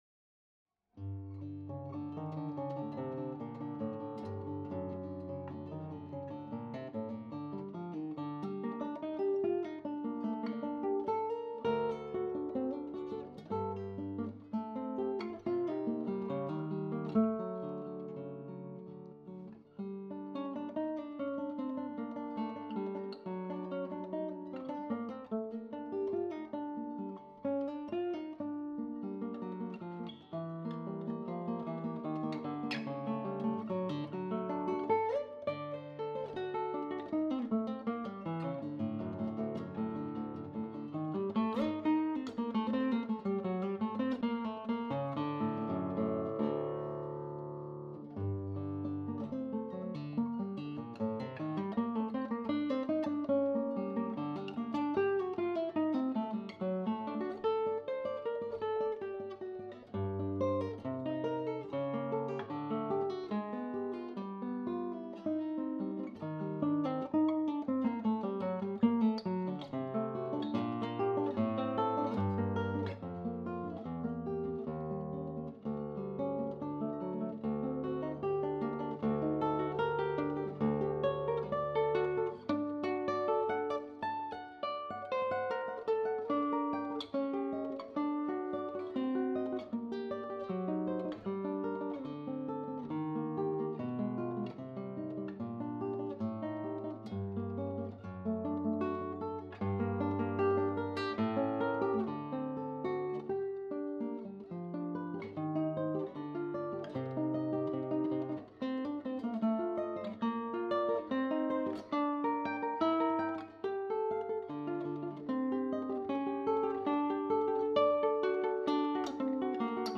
KU277 Rosewood and Spruce Classical Guitar
Lovely full tonal range Classical Guitar with a French Polish finish and Savarez Cristal Soliste 570CR strings.